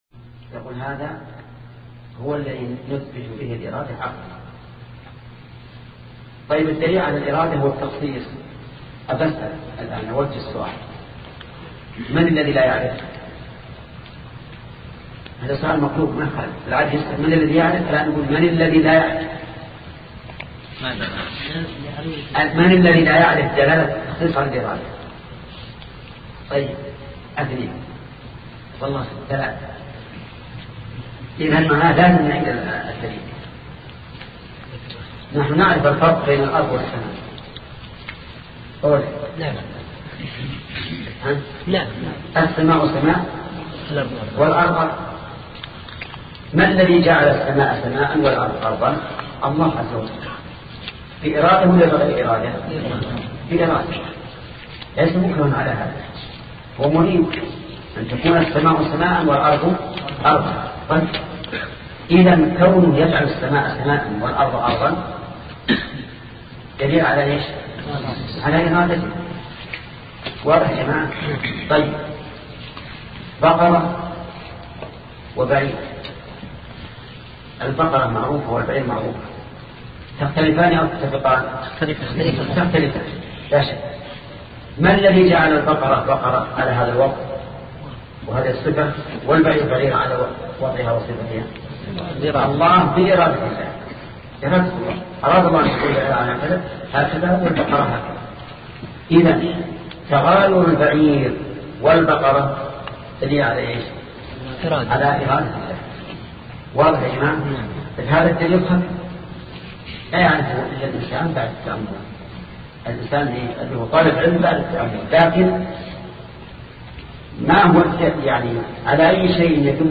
سلسلة مجموعة محاضرات شرح العقيدة السفارينية لشيخ محمد بن صالح العثيمين رحمة الله تعالى